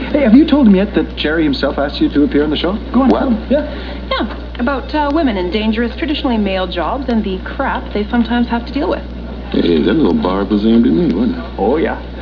Here's a little sample exchange among the three of them.